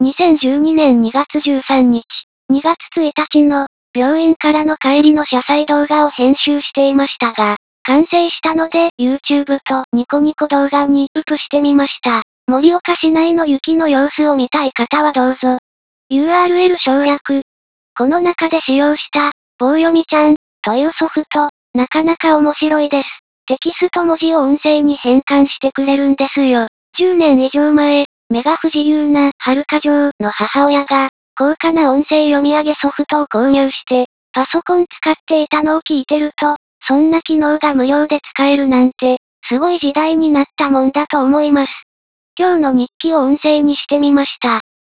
今日の日記を音声にしてみました。